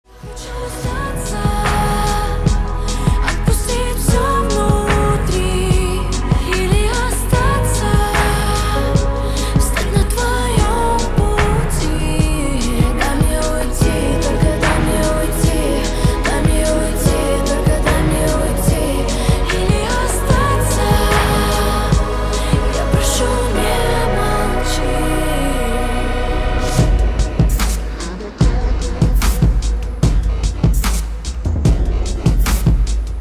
• Качество: 320, Stereo
поп
красивый женский голос